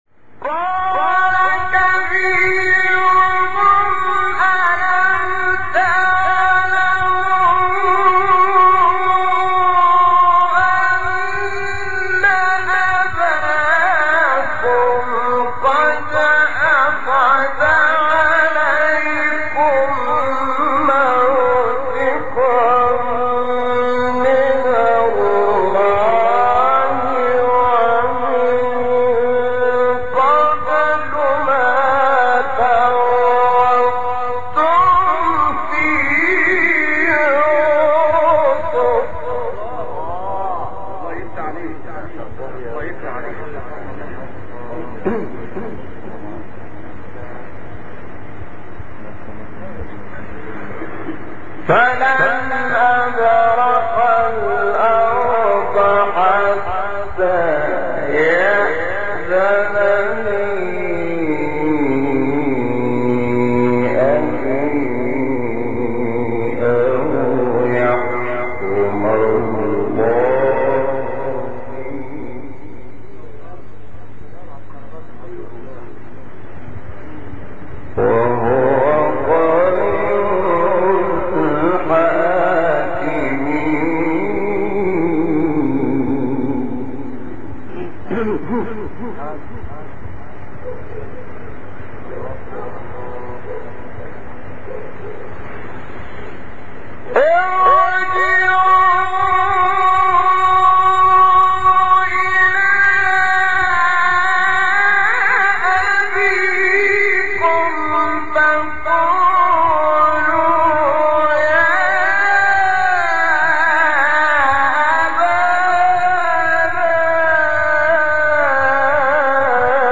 سوره : یوسف آیه: 80-81 استاد : شحات محمد انور مقام : سه گاه قبلی بعدی